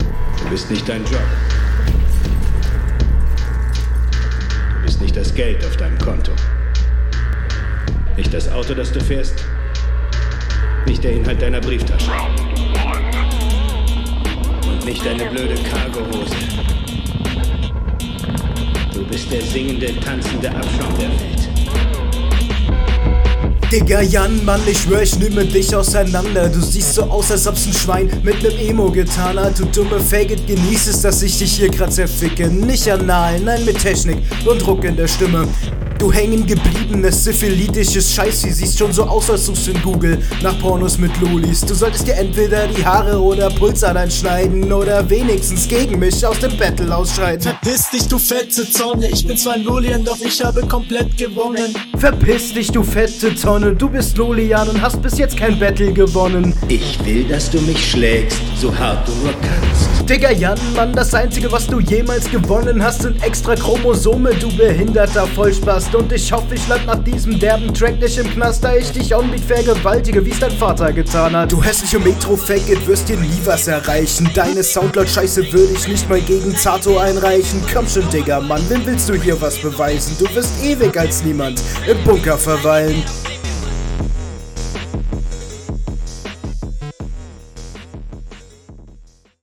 flash_on Driveby Battle Schnelles Battle Format event 07.05.2024 07:38 visibility 495 Aufrufe timer Lädt...